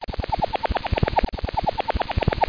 00748_Sound_fireflight.mp3